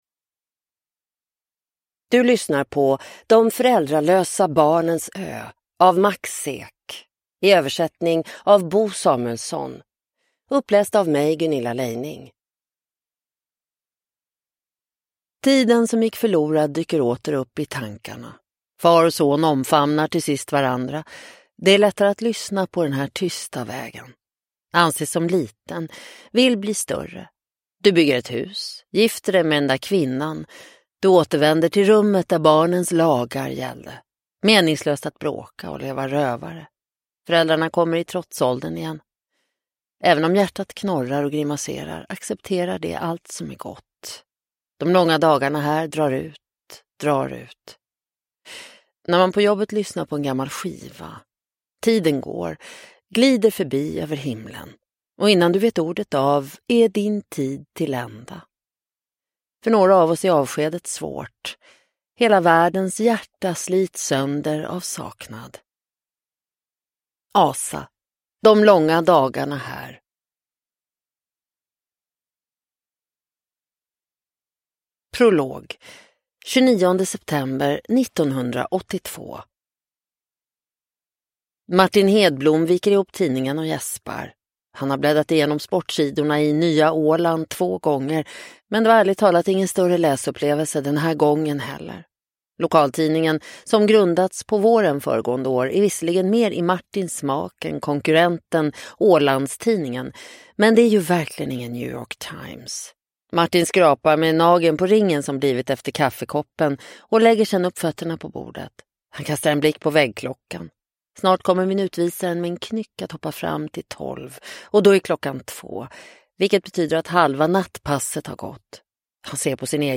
De föräldralösa barnens ö – Ljudbok